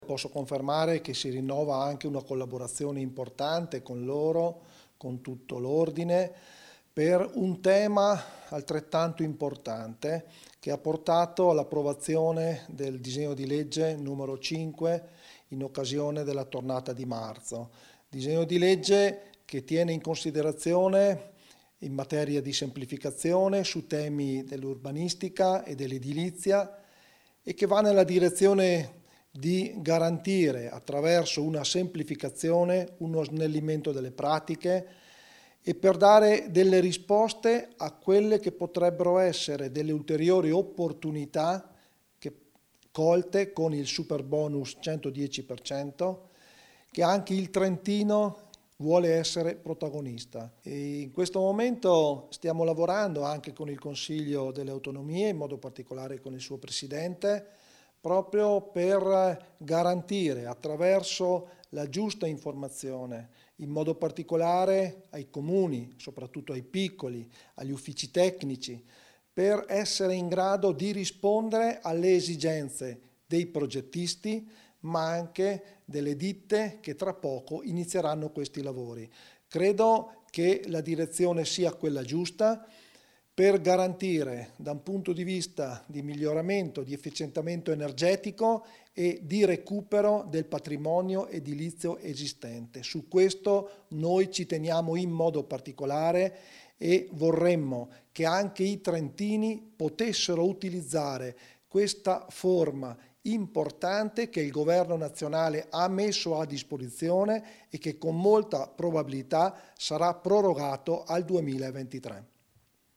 Int_Tonina_Assemlea_Ordine_Architetti.mp3